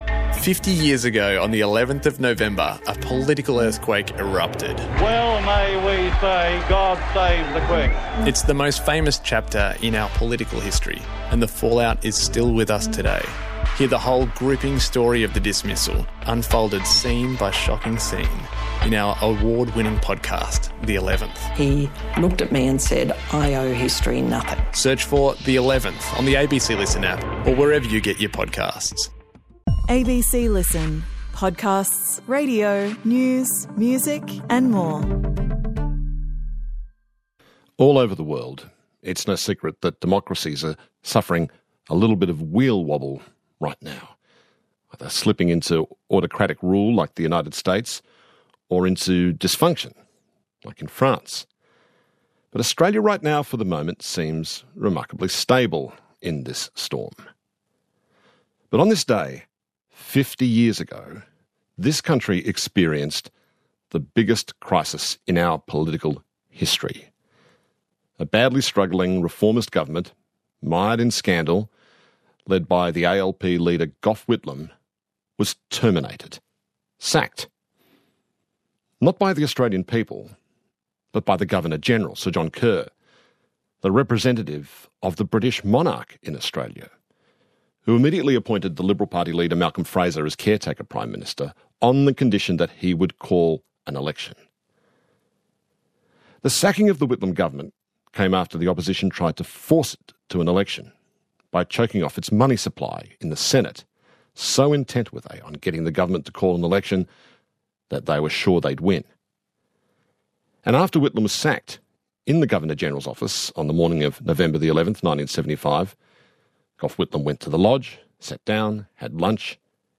Hosted by Richard Fidler and Sarah Kanowski, Conversations is the ABC's most popular long-form interview program.